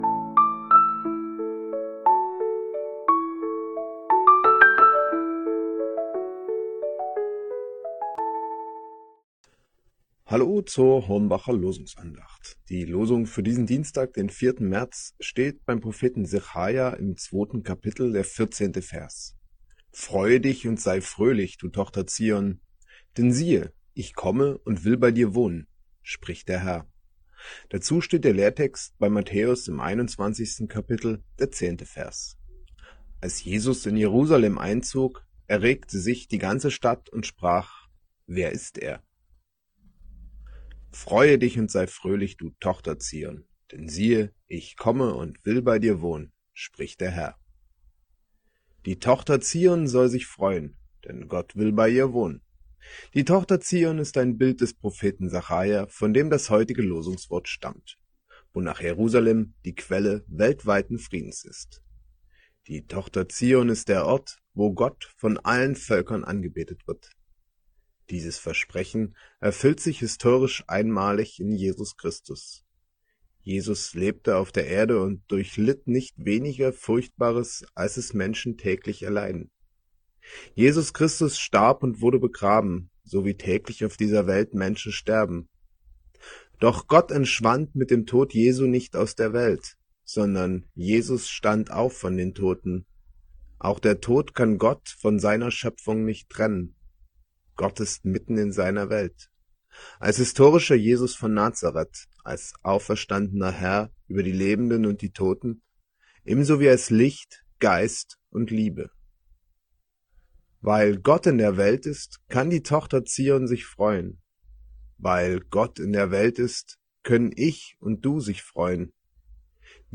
Losungsandacht für Dienstag, 04.03.2025